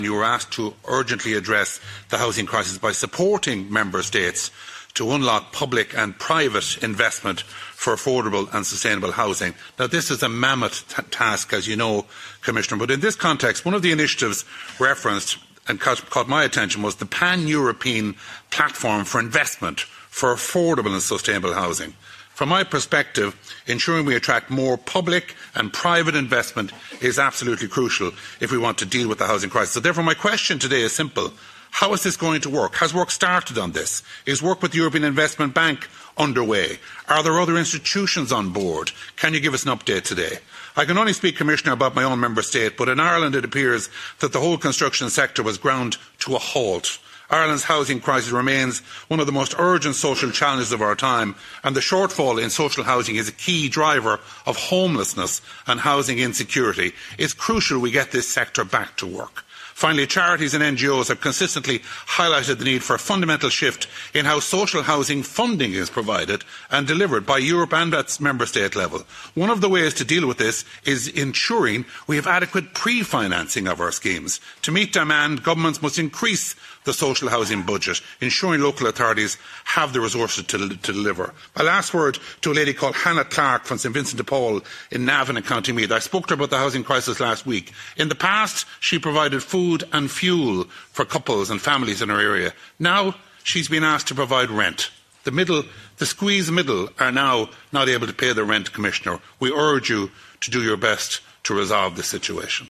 The Commissioner has been tasked with supporting Member States in mobilising both public and private funding to address housing shortages, and Mr Mullooley told the committee there is a need for immediate progress…………
mep-mullooly-housing-committee-meeting-audio-mp3.mp3